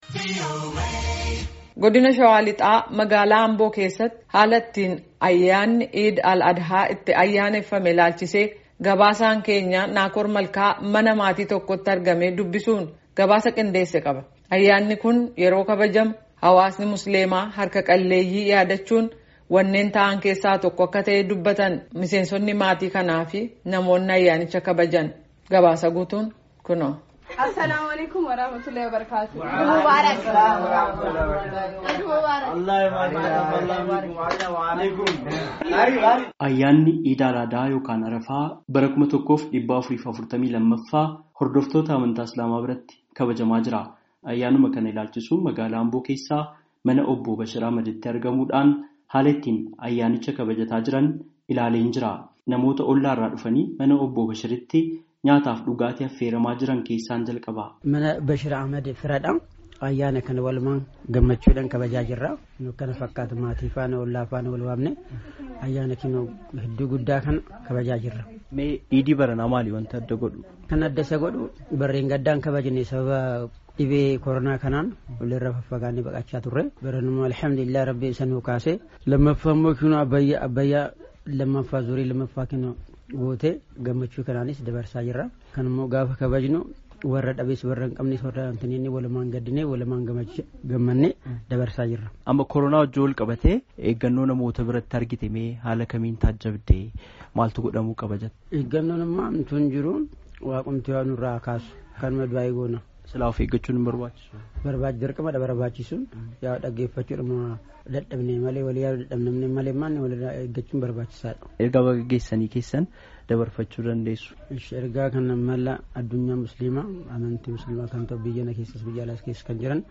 mana maatii tokkootti argamee dubbisuun gabaasaa qindeesse qaba.